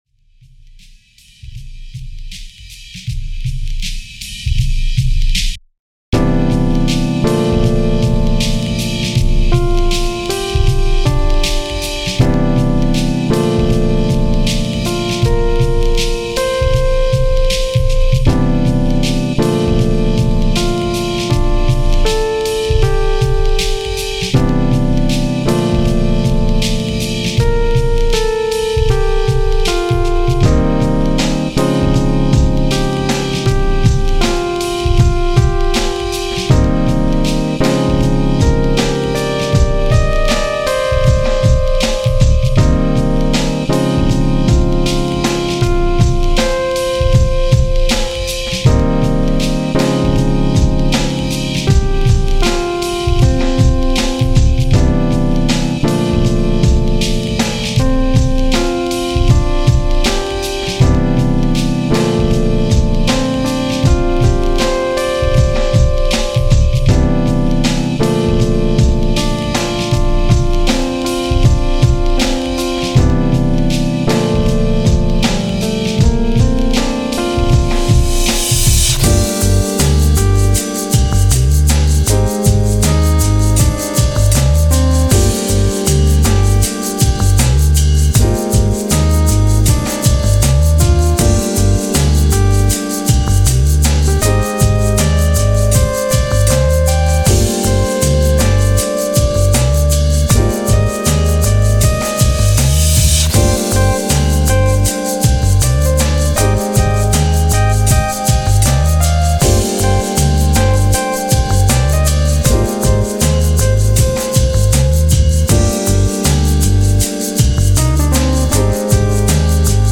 for that drum drop.